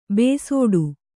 ♪ bēsōḍu